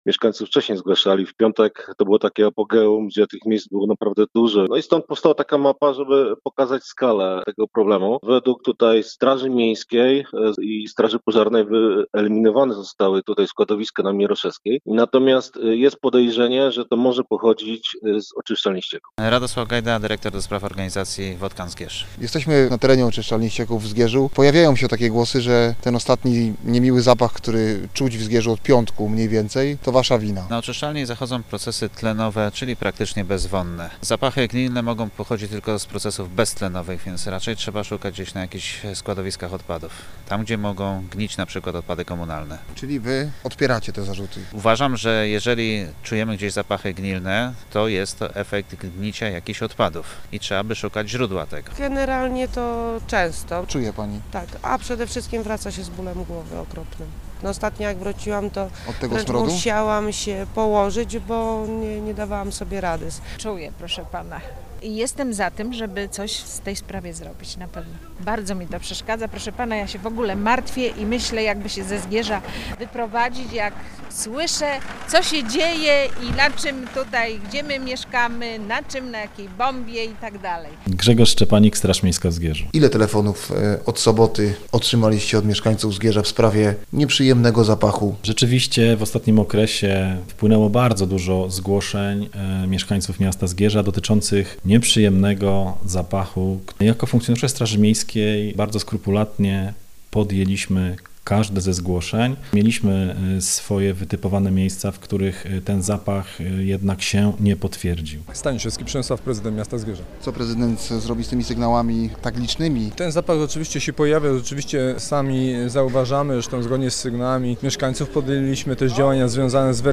Posłuchaj relacji i dowiedz się więcej: Nazwa Plik Autor Nieprzyjemny zapach roznosi się nad Zgierzem.